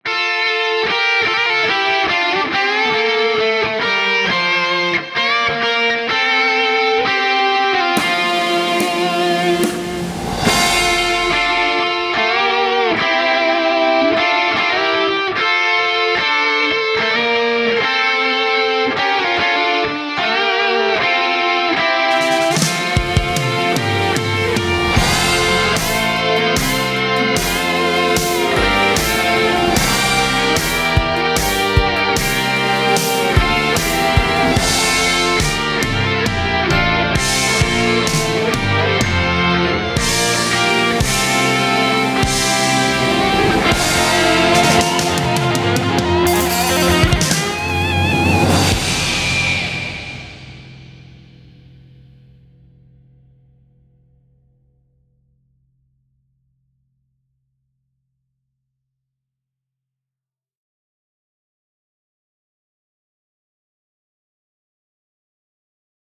Click HERE to download the MP3 Backing Track.